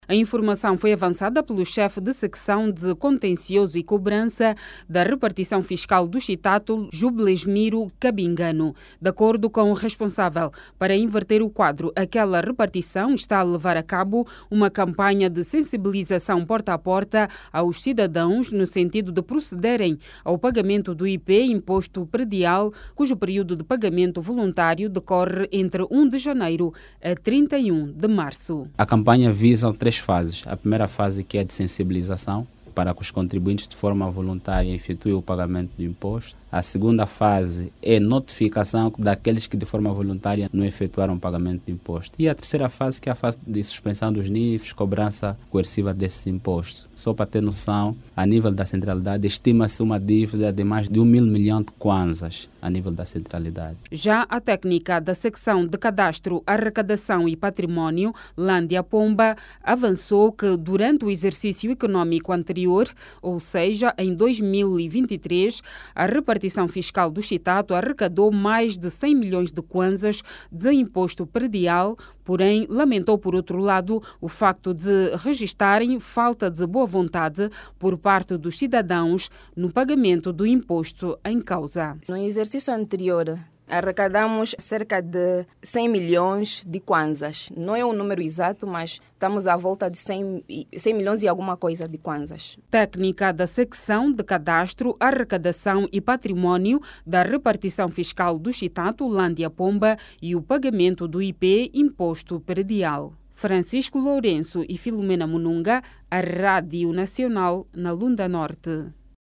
Os moradores da Centralidade do Mussungue, na Lunda-Norte, devem mais de mil milhões de Kwanzas de Imposto Predial. Neste momento, decorre uma campanha de sensibilização porta-a-porta, para a cobrança do Imposto Predial, cujo prazo de pagamento voluntário terminou em Março. Clique no áudio abaixo e ouça a reportagem